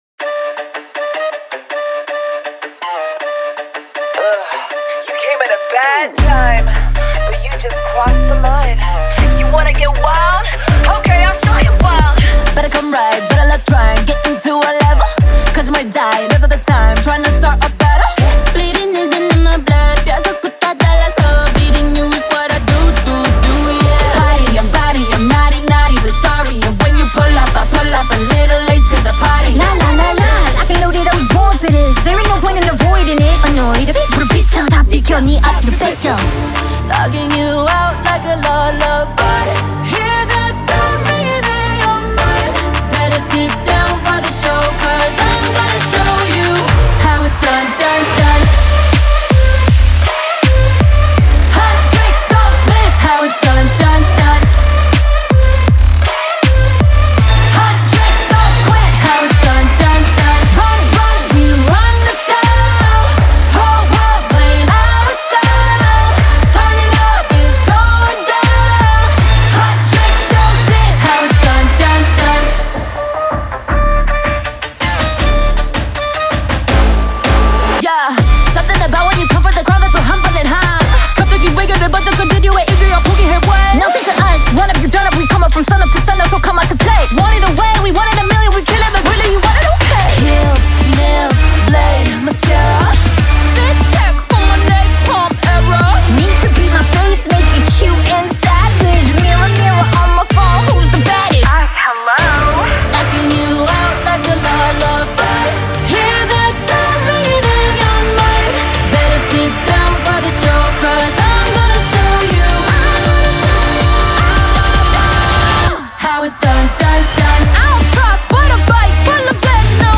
BPM160-160
Audio QualityPerfect (High Quality)
K-Pop song for StepMania, ITGmania, Project Outfox
Full Length Song (not arcade length cut)